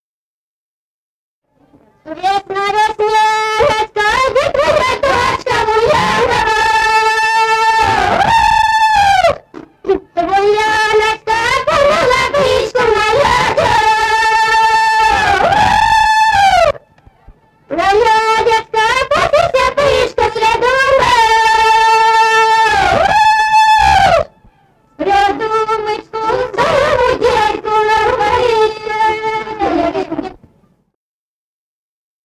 Народные песни Стародубского района «Весна, весняночка», весняная девичья.